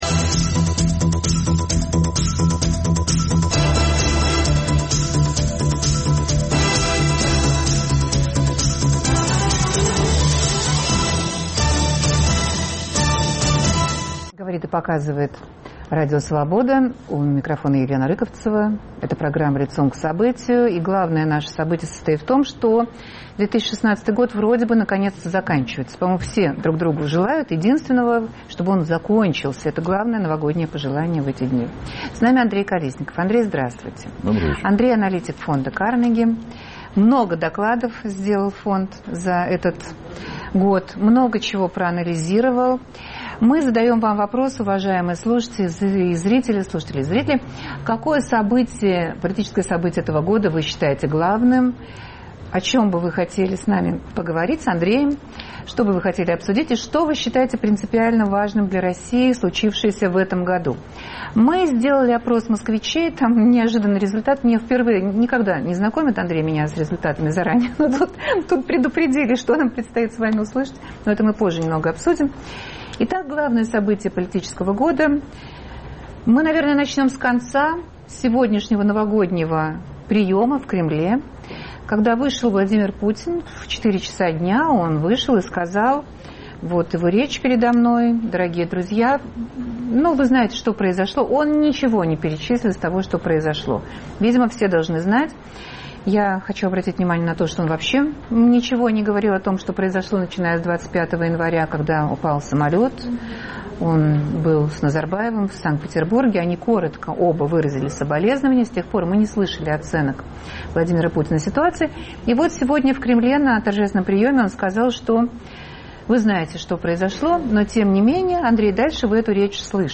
Чем для России был 2016-й? Гость студии